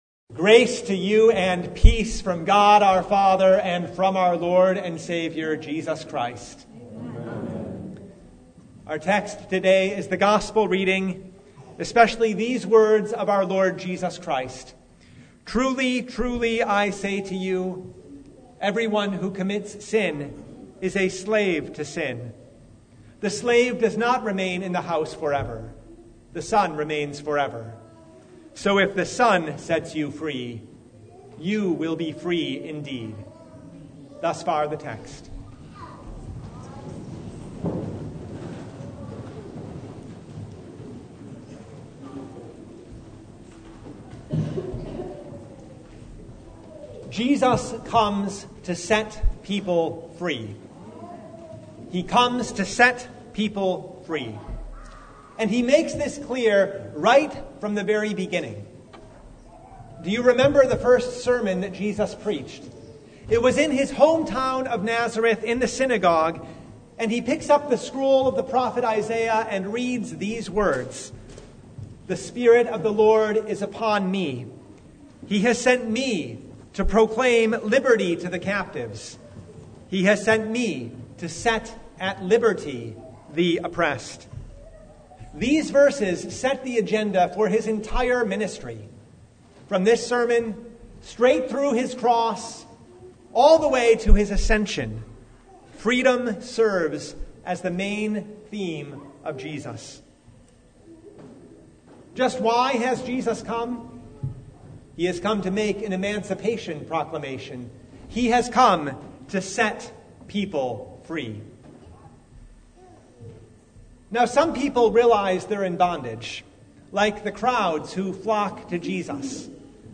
John 8:31-36 Service Type: The Festival of the Reformation Jesus came to set people free